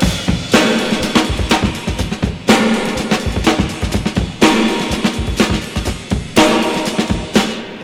123 Bpm Drum Loop Sample A Key.wav
Free drum groove - kick tuned to the A note.
123-bpm-drum-loop-sample-a-key-7Zp.ogg